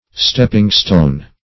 Stepping-stone \Step"ping-stone`\, n.